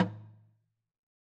LogDrumLo_MedM_v3_rr2_Sum.wav